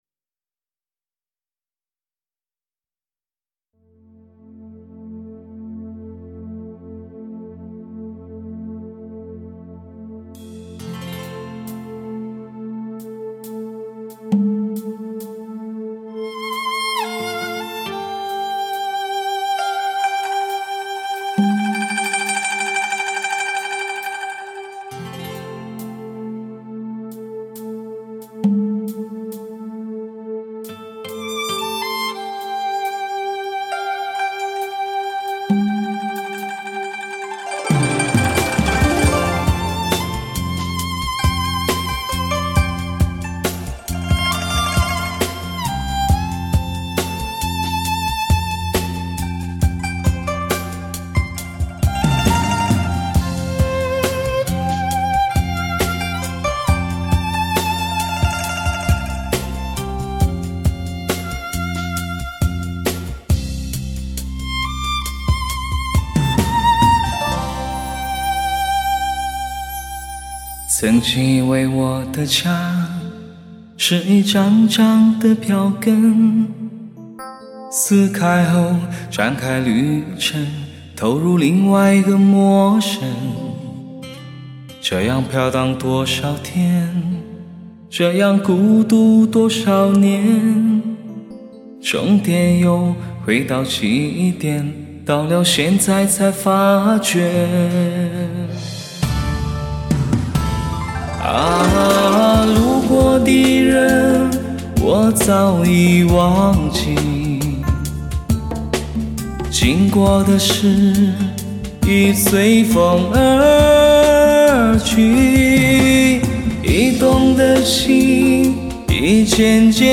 母带级音质 发烧新体验
全面恢复黑胶唱片的空气感和密度感
低音强劲有力，中音清晰丰满，高音柔和圆润，精确的乐器定位，清晰的人声，层次分明，声场辽阔。